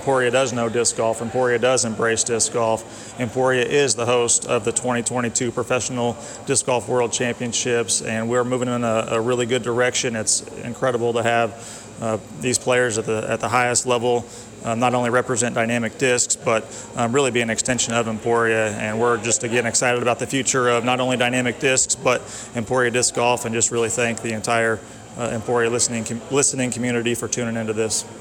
‘Validation;’ Dynamic Discs ownership and newest team members reflect on magnitude of recent signings during press conference Tuesday